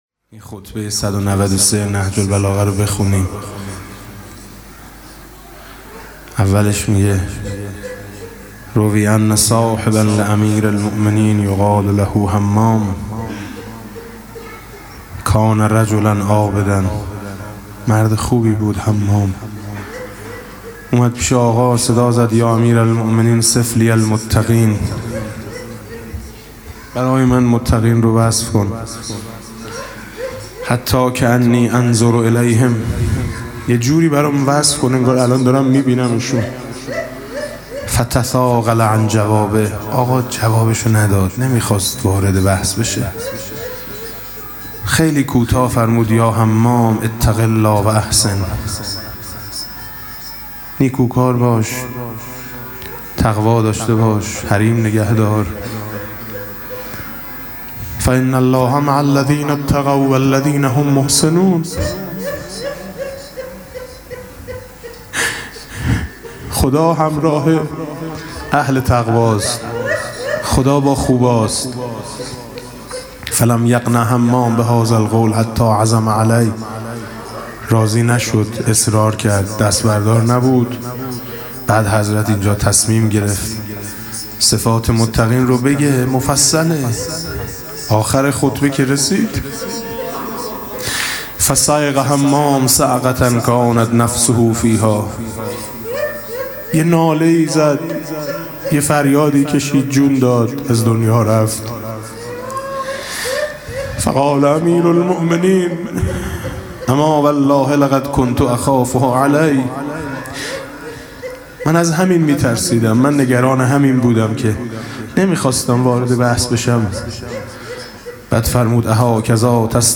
مناسبت: روضه‌ی هفتگی و قرائت زیارت امام زمان (عج)